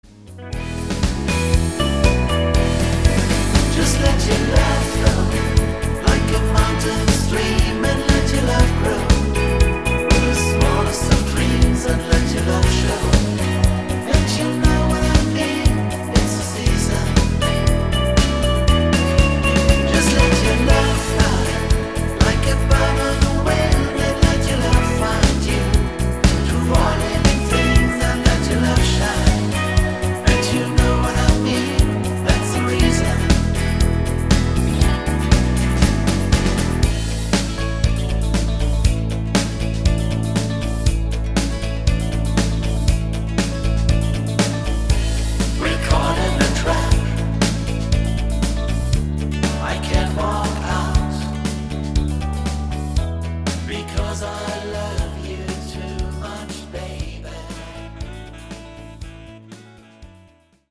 super gutes und tanzbares Medley